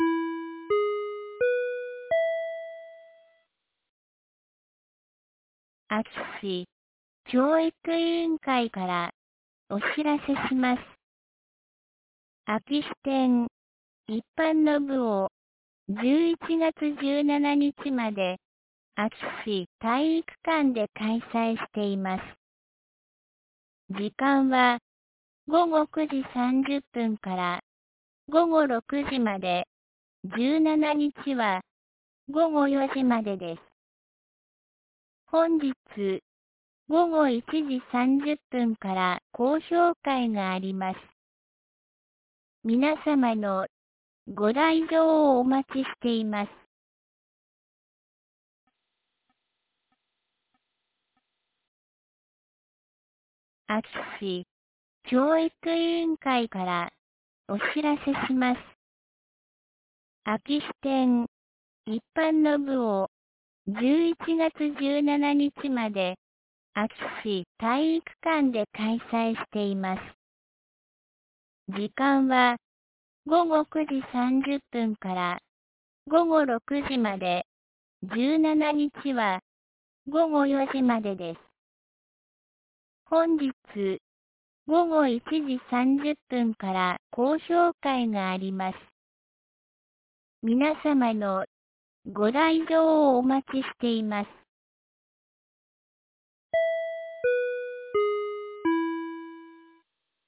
2024年11月10日 12時11分に、安芸市より全地区へ放送がありました。